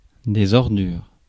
Ääntäminen
Ääntäminen France: IPA: [dɛ.z‿ɔʁ.dyʁ] Tuntematon aksentti: IPA: /ɔʁ.dyʁ/ Haettu sana löytyi näillä lähdekielillä: ranska Käännös 1. rämps 2. jäätmed Suku: f .